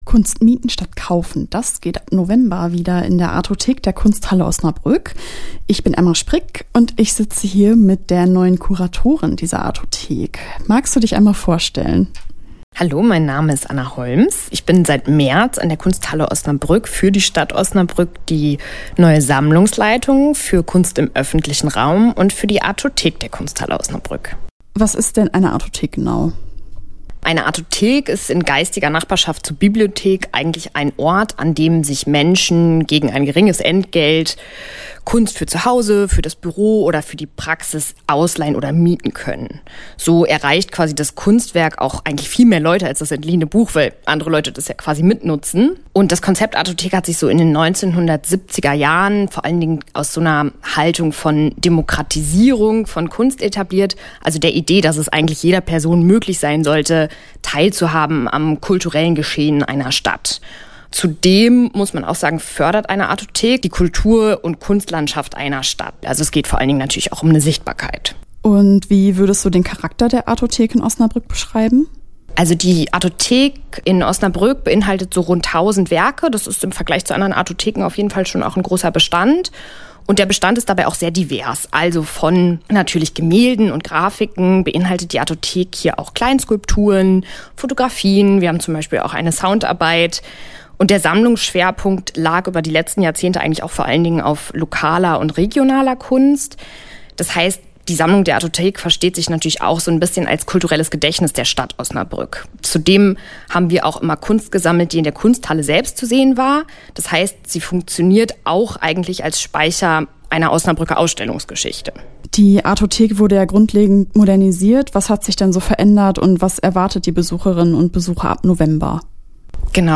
Das ausführliche Interview